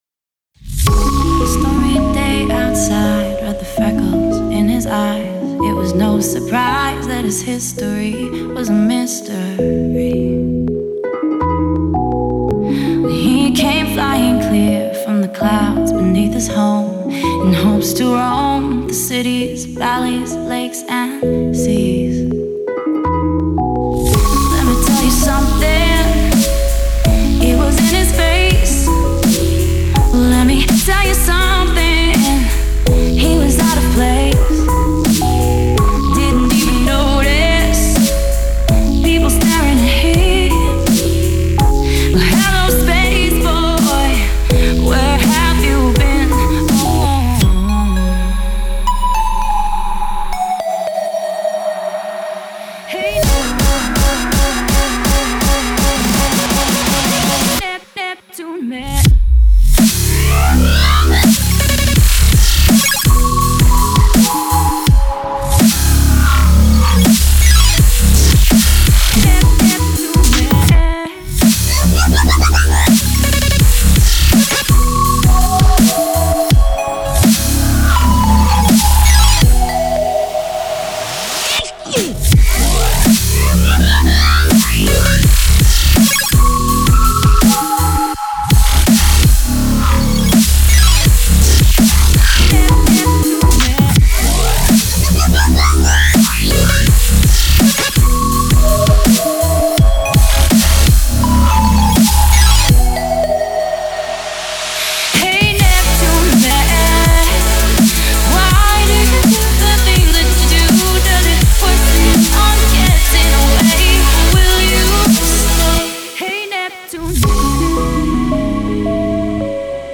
Категория: Электро музыка » Дабстеп